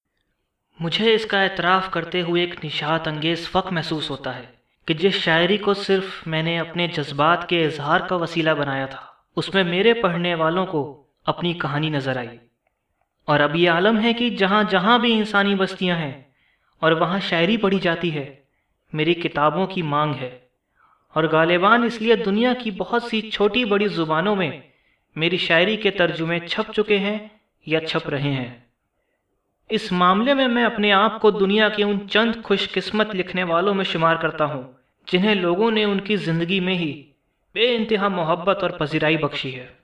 Medium Base, Smooth, Pleasant, Soft &#61558
My Dialect is Hindi-Urdu.
Sprechprobe: eLearning (Muttersprache):